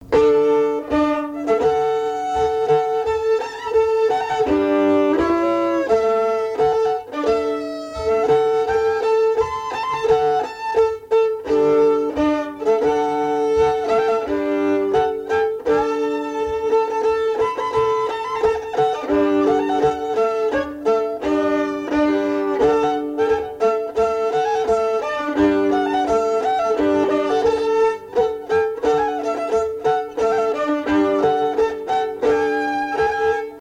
Mémoires et Patrimoines vivants - RaddO est une base de données d'archives iconographiques et sonores.
circonstance : fiançaille, noce
Pièce musicale inédite